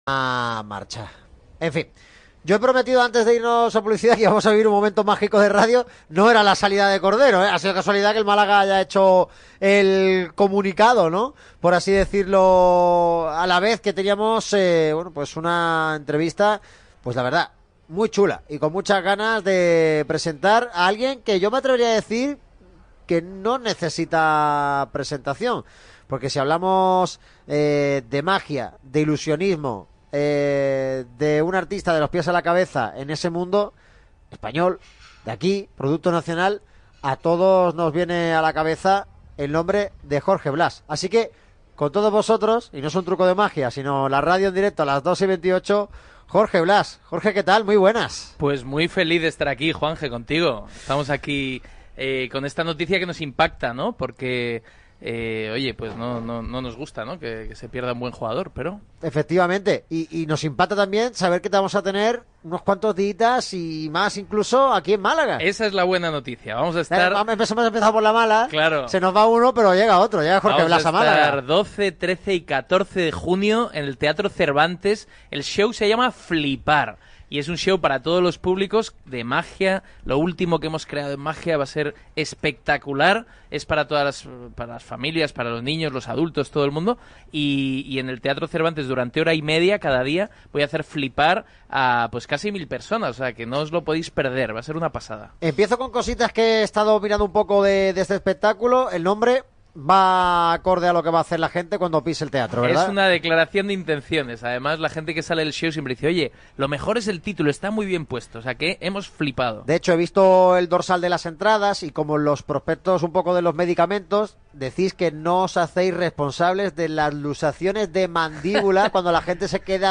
El reconocido ilusionista y mago español, Jorge Blas, se ha pasado por el programa de este lunes en Radio MARCA Málaga. El mago ha dejado boquiabiertos al equipo de la radio del deporte y a todos sus oyentes con sus espectaculares trucos de magia en restaurante Araboka Plaza en calle Compositor Lehmberg Ruiz, 28. Jorge Blas ha sido entrevistado